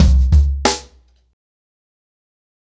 Expositioning-90BPM.21.wav